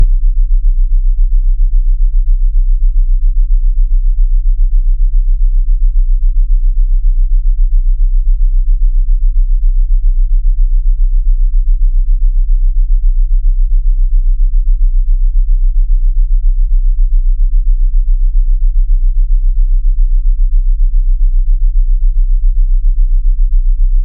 Генерирую звуки без обертонов. Я так понимаю чем ближе волны 2 сигналов, тем они более медленнее взаимовычитаются и поэтому это вызывает такое отторжение? взял до и си на - 2 октавы, слышны прям биения.